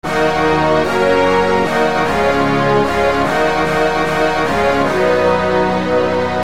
描述：管弦乐铜管/弦乐组合
Tag: 75 bpm Hip Hop Loops Brass Loops 1.08 MB wav Key : Unknown